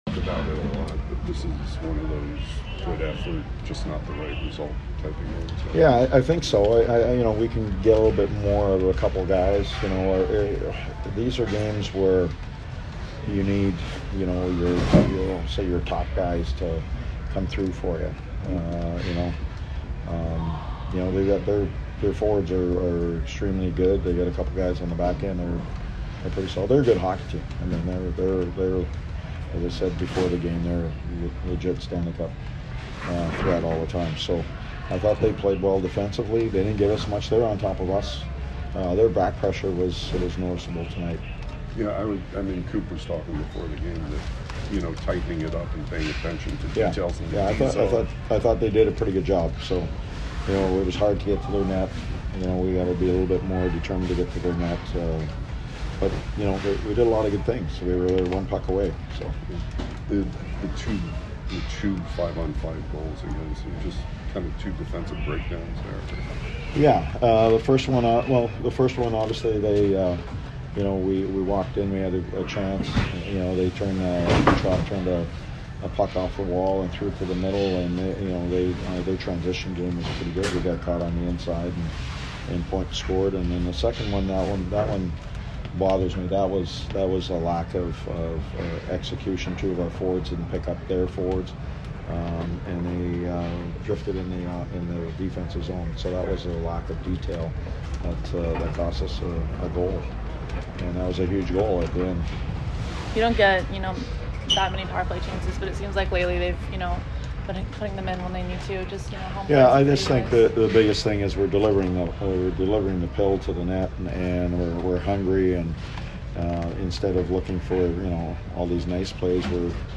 Barry Trotz post-game 2/8